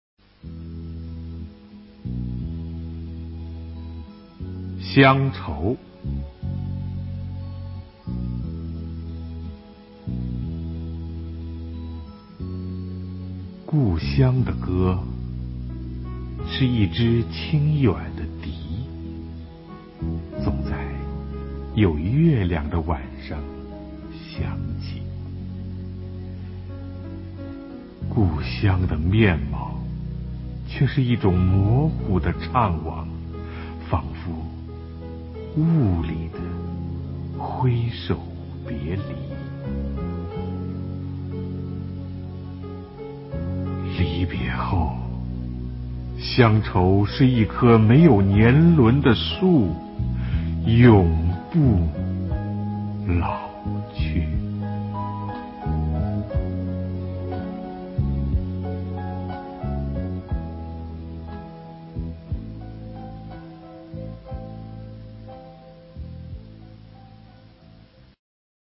首页 视听 经典朗诵欣赏 席慕容：委婉、含蓄、文雅